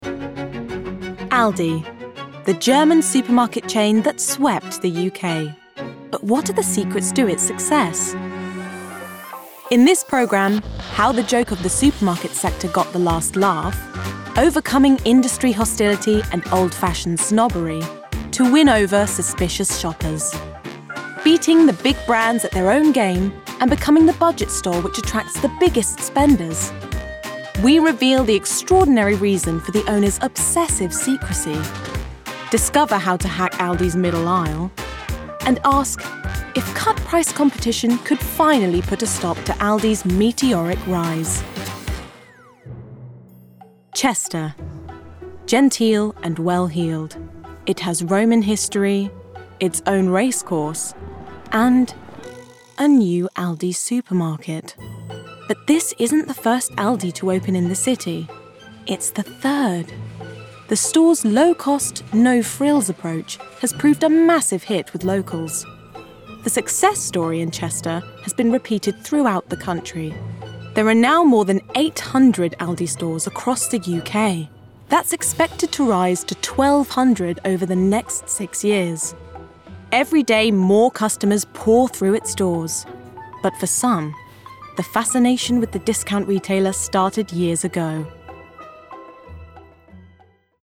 Voice Reel
TV Narration